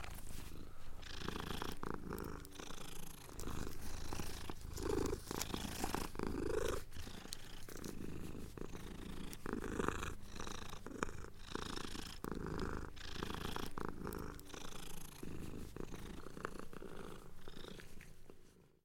cat-sound